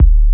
50BASS01  -R.wav